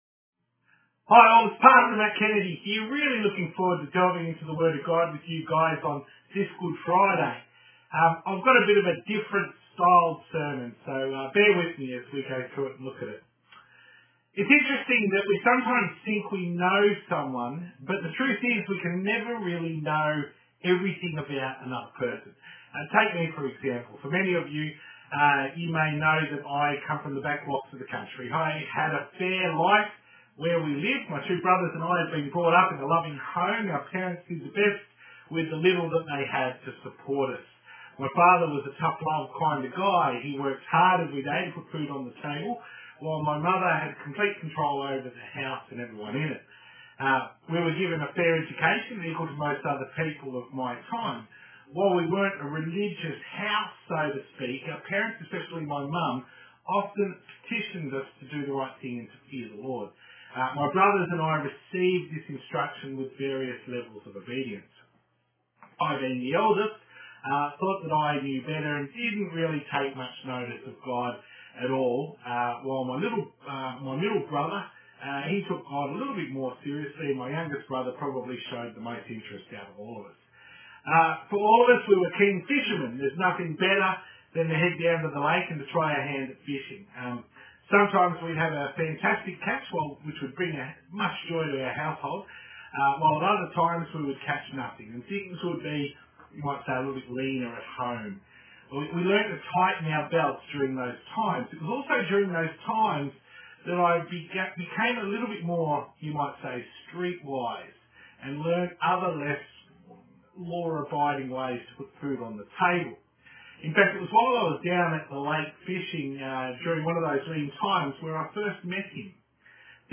sermon_-the-thief-on-the-cross-sd-480p_01.mp3